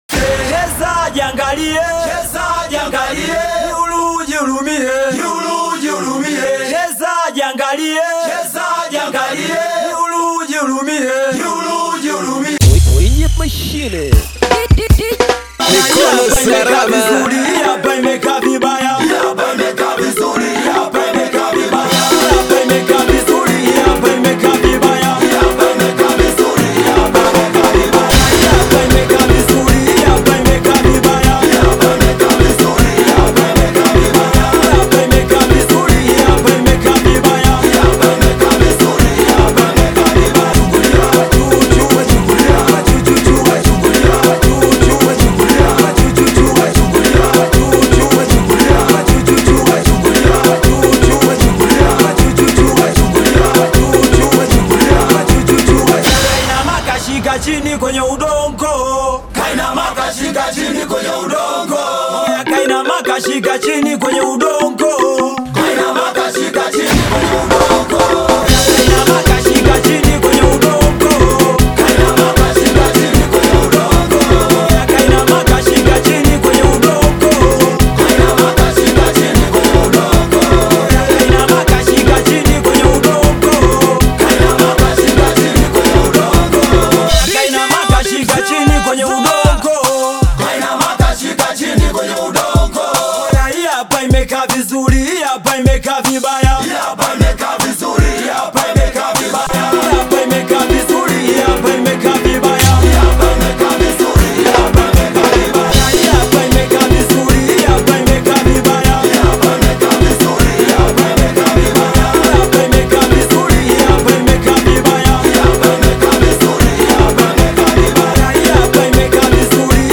Known for his energetic Singeli sound and fast-paced beats